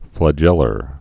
(flə-jĕlər)